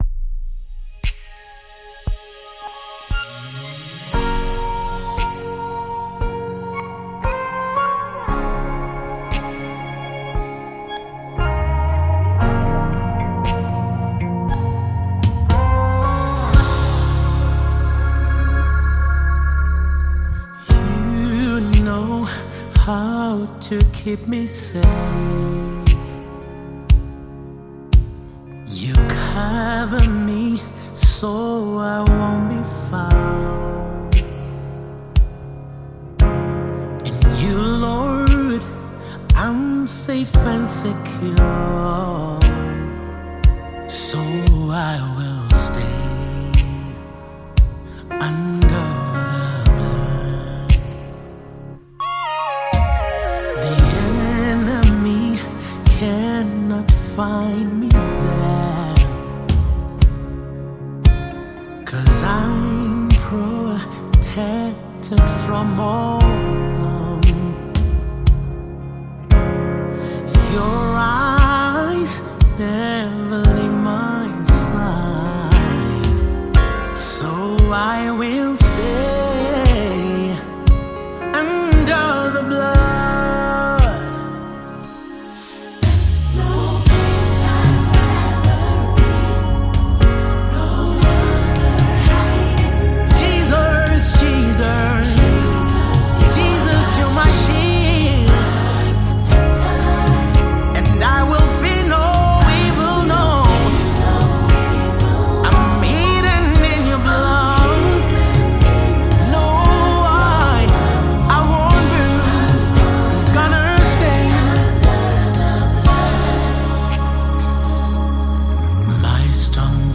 Mp3 Gospel Songs
South African gospel music artist and preacher